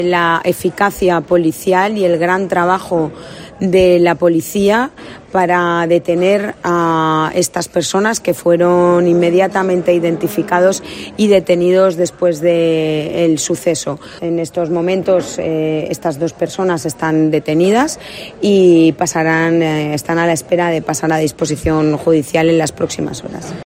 AUDIO: Escucha las palabras de la delegada del Gobierno en la Comunidad Valenciana